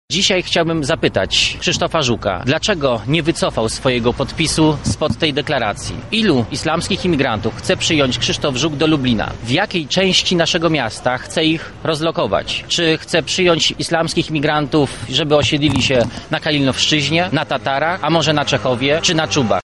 – mówi poseł Sylwester Tułajew.